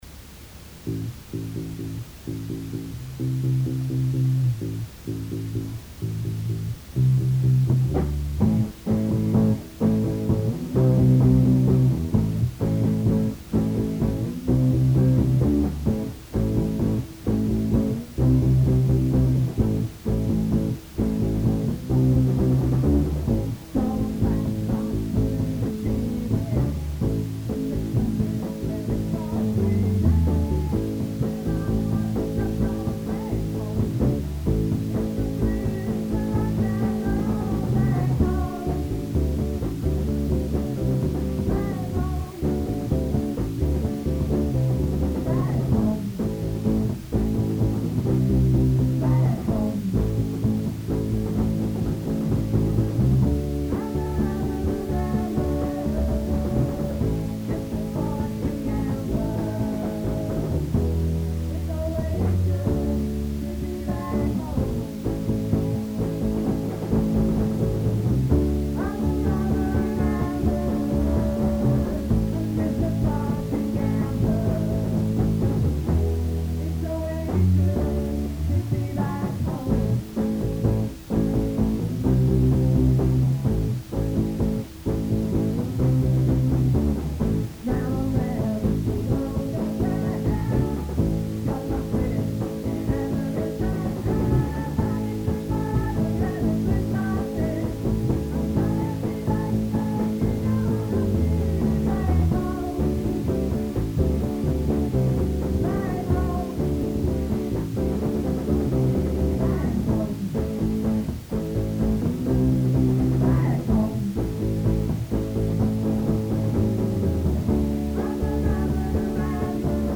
Lead Vocals and Guitar
Bass
Lead Vocals
Drums
Lead Guitar
Here are a few Orpheus covers recorded during a practice on Sunday 14th of June, 1976!: